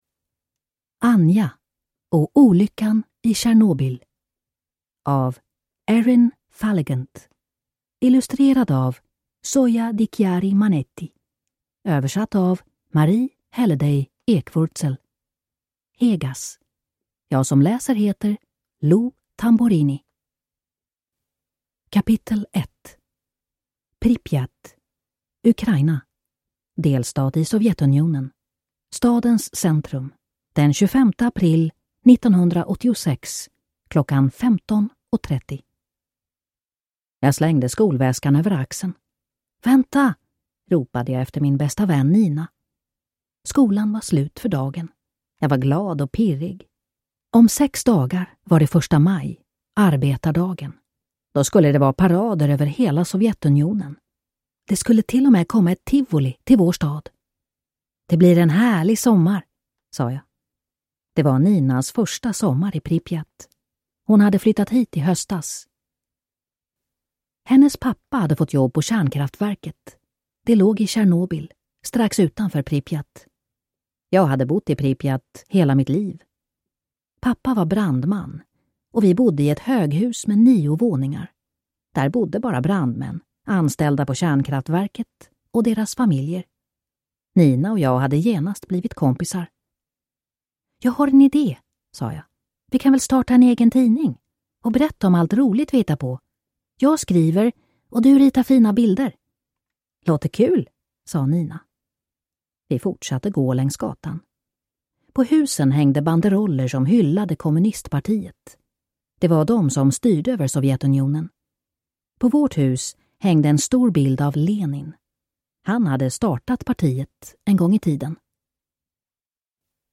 Anja och olyckan i Tjernobyl (ljudbok) av Erin Falligant